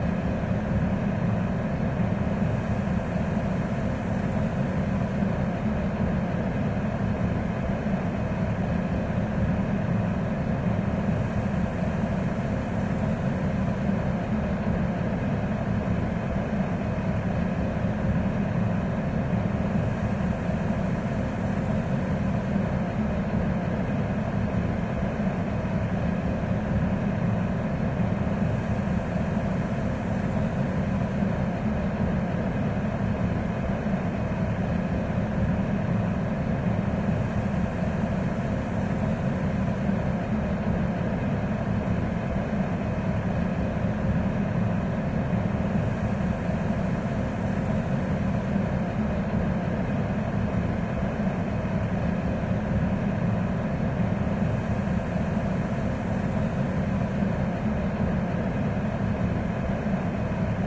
Type BGM
Speed 150%